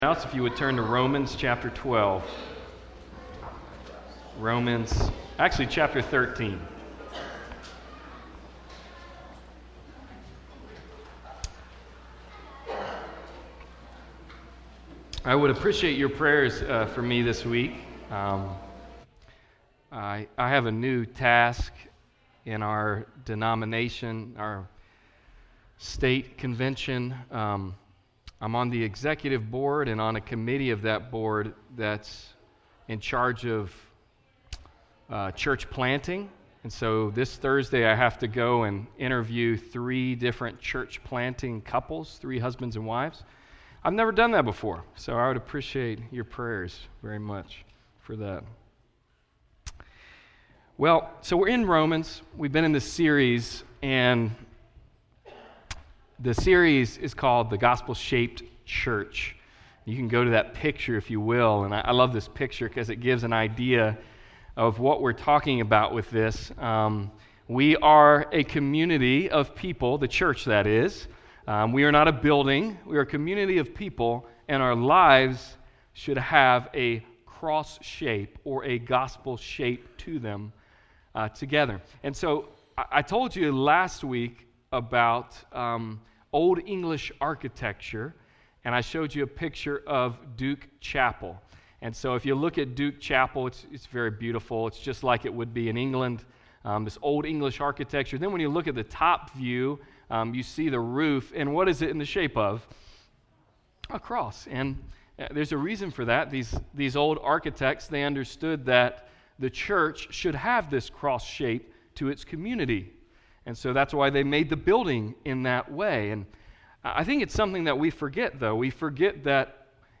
Passage: Romans 13:1-7 Service Type: Sunday Service